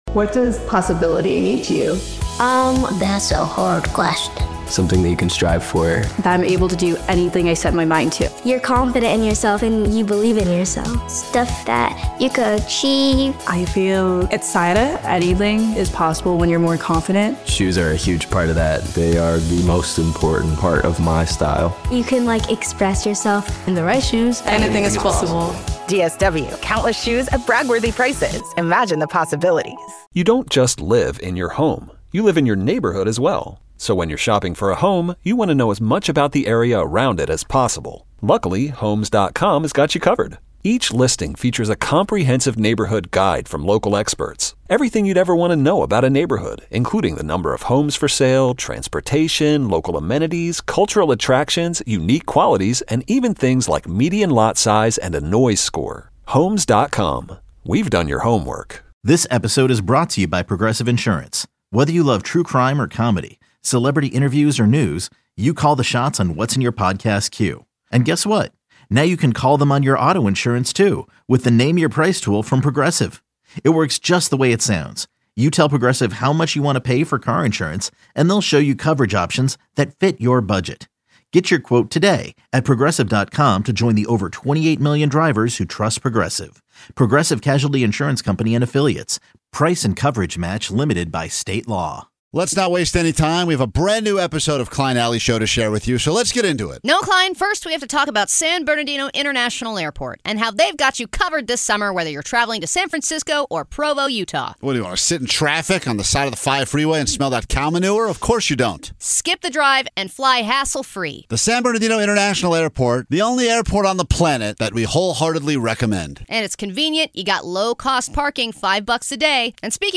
With a loyal, engaged fanbase and an addiction for pushing boundaries, the show delivers the perfect blend of humor and insight, all while keeping things fun, fresh, and sometimes a little bit illegal.